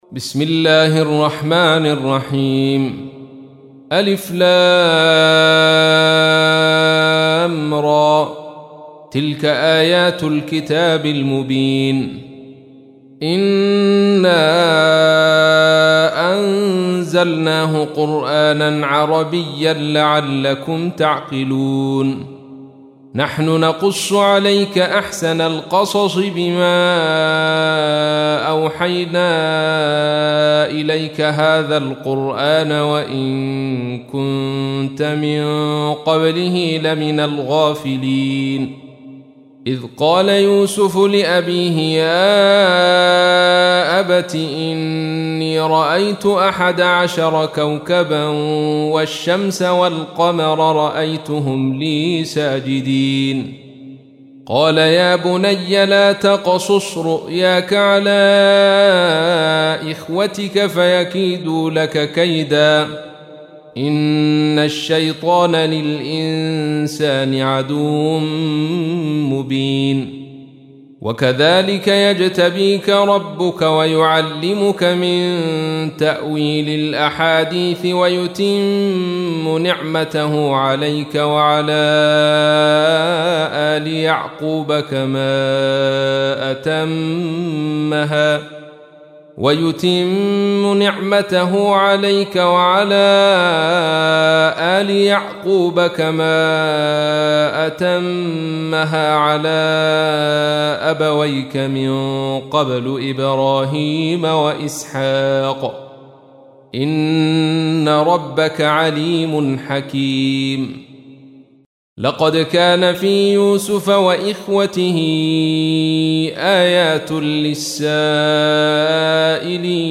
تحميل : 12. سورة يوسف / القارئ عبد الرشيد صوفي / القرآن الكريم / موقع يا حسين